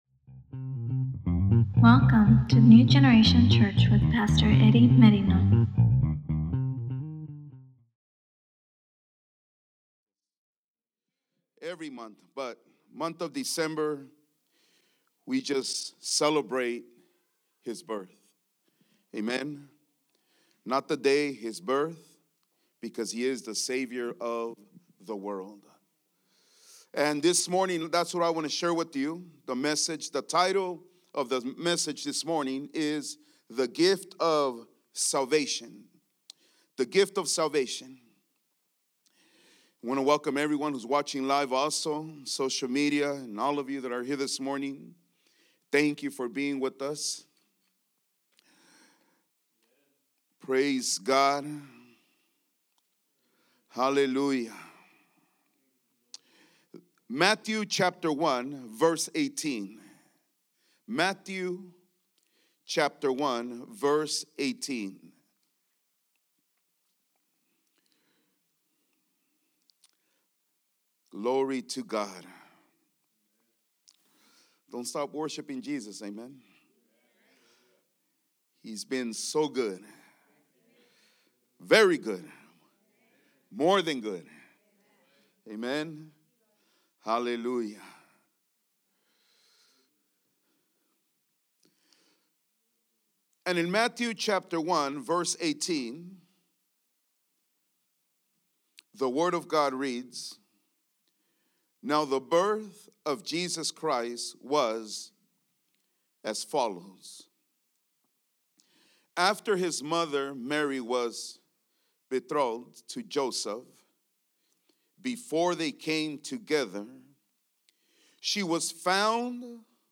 ECNG English Sunday Sermons